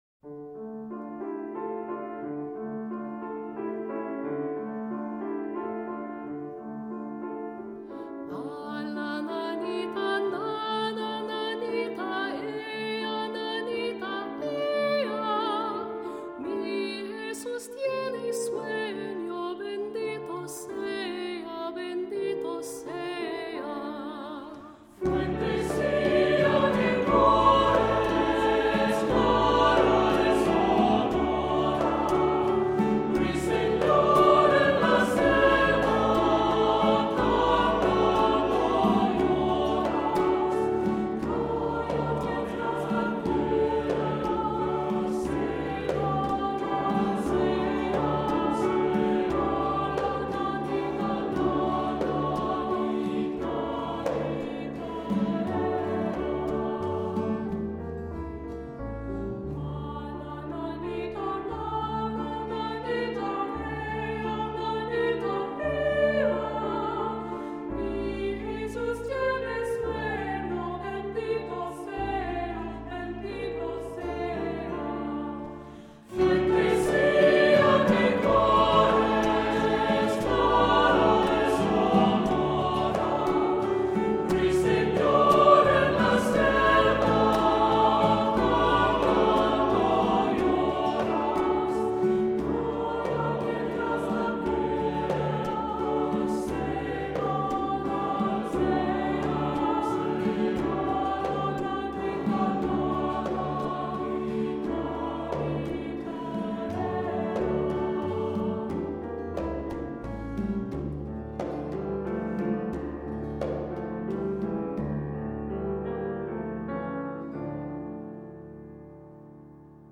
Accompaniment:      Piano, Guitar
Music Category:      Choral